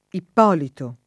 ipp0lito] pers. m. — ant.